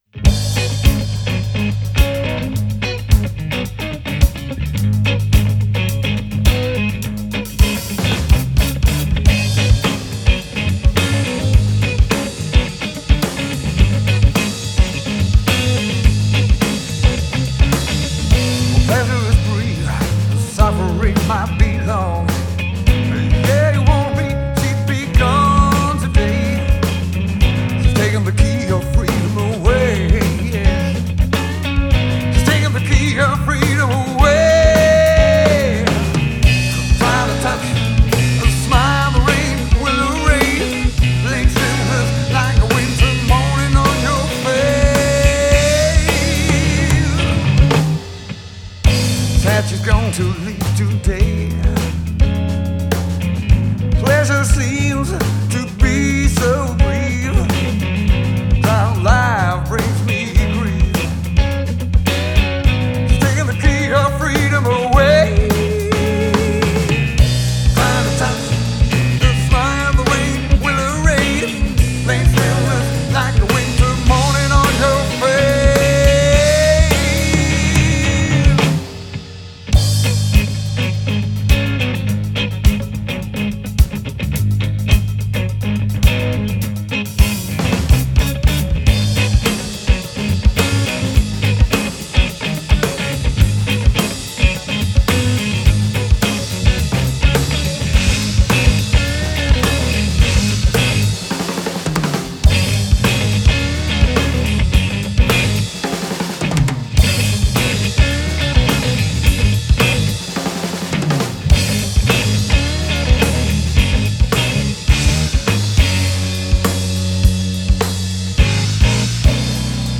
Rock&Blues